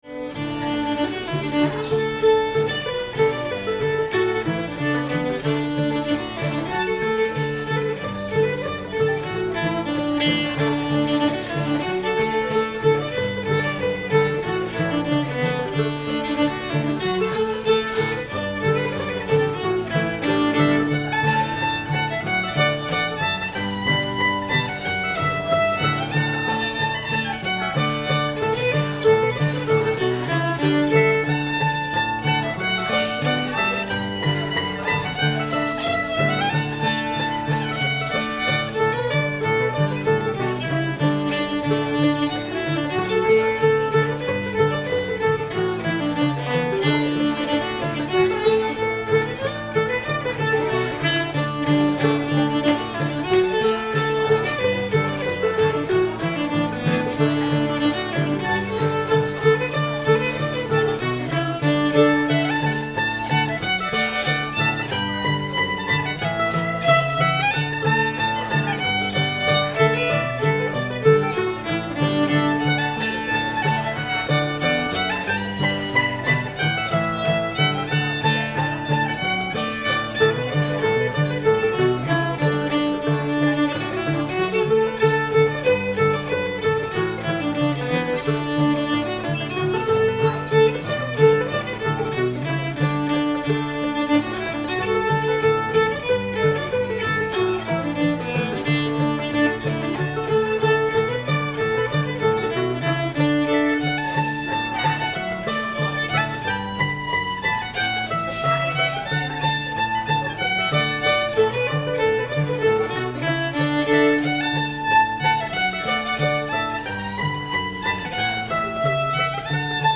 duck river [D]